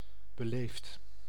Ääntäminen
IPA: /be.le:fd/